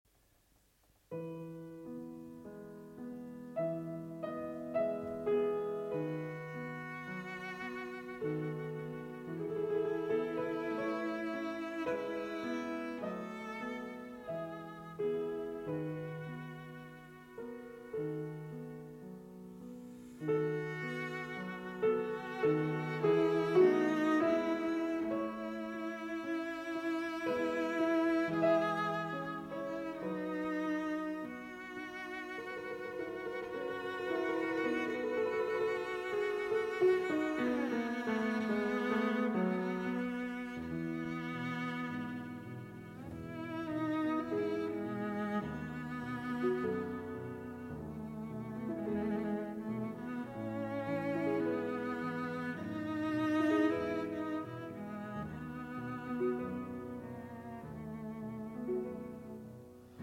Piano
Cello Sonata in A Major